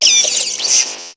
Cri de Raichu dans Pokémon HOME.